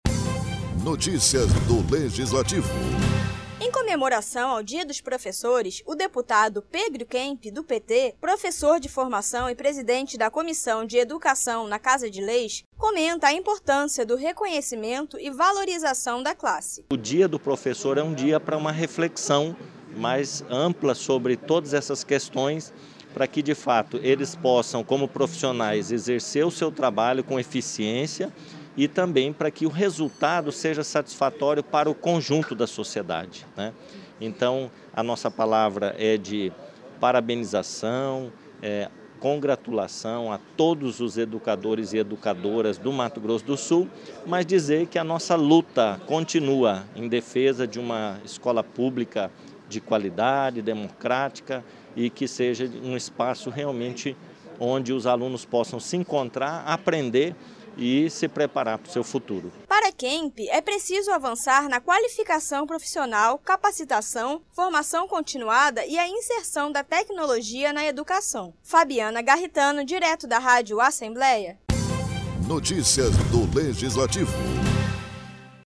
No Dia do Professor, o deputado estadual Pedro Kemp, do PT usou a tribuna para homenagear os trabalhadores e fez uma reflexão sobre os desafios da educação.